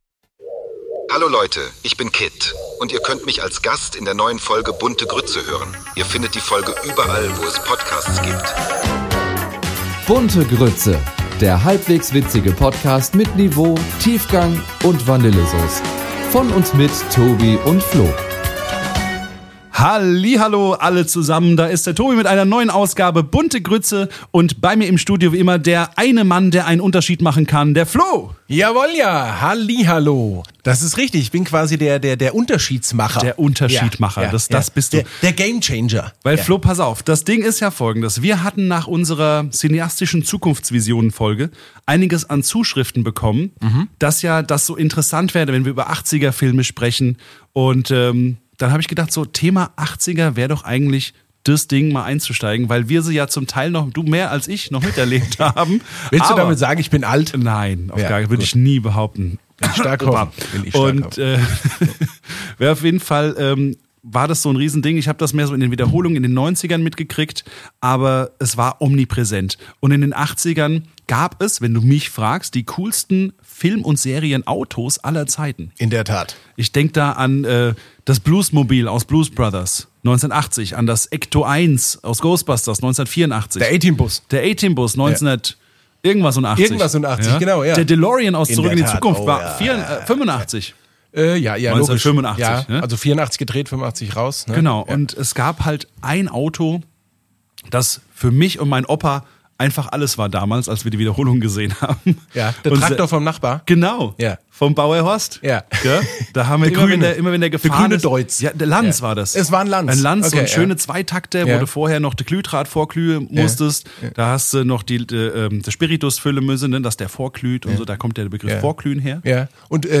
Die Jungs haben die einmalige Gelegenheit, mit K.I.T.T. zu sprechen und sogar eine Runde mit dem faszinierenden Auto zu fahren. In dieser Episode teilen sie ihre Erinnerungen an die Serie, diskutieren über die Faszination von K.I.T.T. und lassen den Kindheitstraum Wirklichkeit werden.